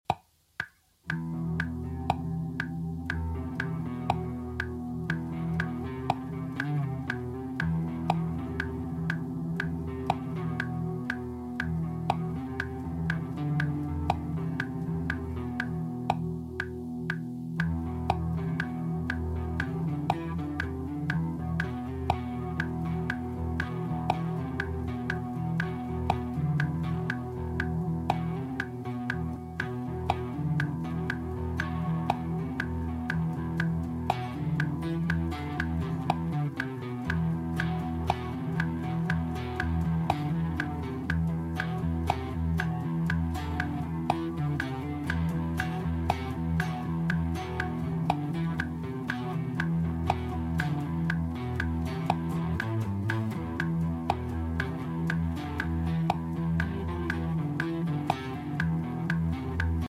apologize for how loud thr metronome is since i was focused on NOT listening to thr delay repeats.